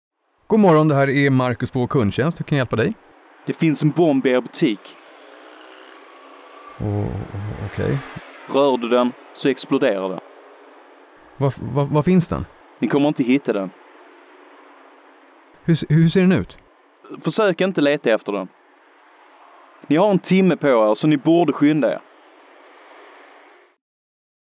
Många bombhot framförs per telefon, men det blir allt vanligare att de kommer via e-post eller sociala medier.